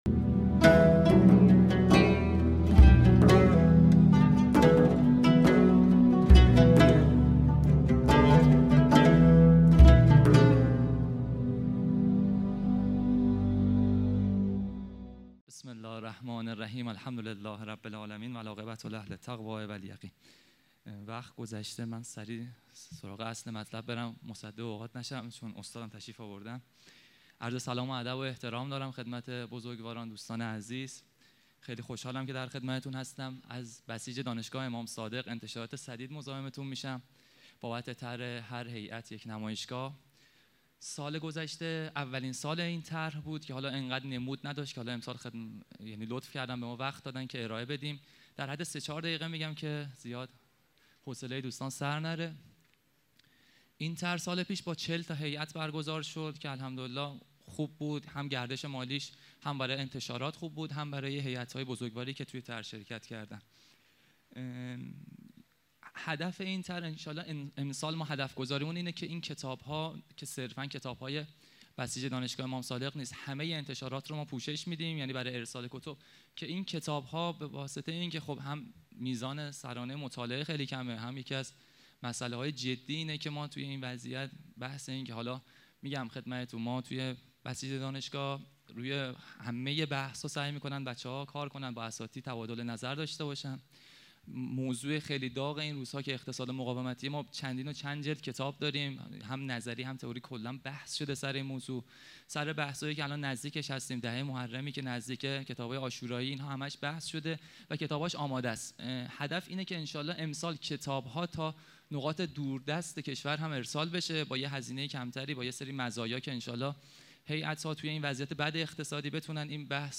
سخنرانی
نهمین همایش هیأت‌های محوری و برگزیده کشور | شهر مقدس قم - مسجد مقدس جمکران - مجتمع یاوران مهدی (عج)